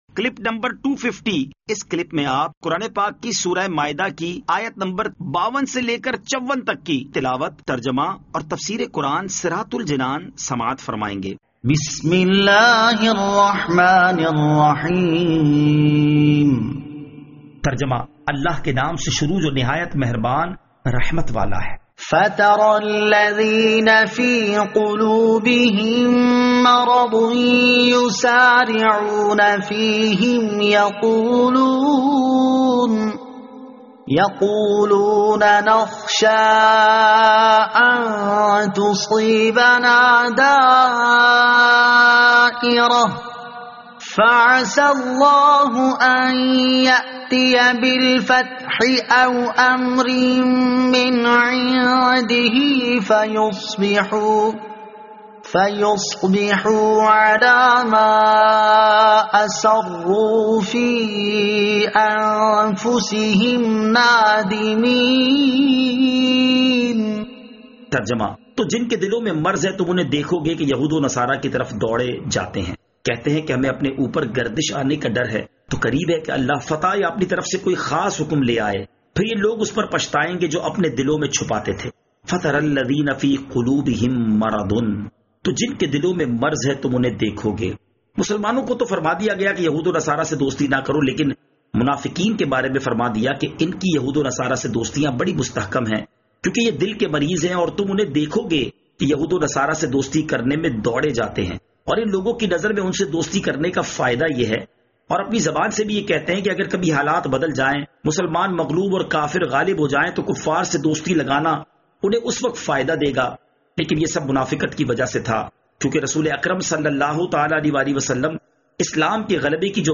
Surah Al-Maidah Ayat 52 To 54 Tilawat , Tarjama , Tafseer